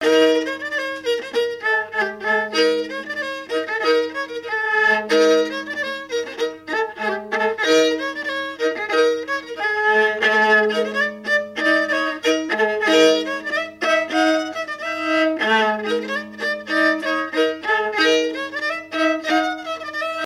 Chants brefs - A danser
Bocage vendéen
danse : branle : avant-deux ;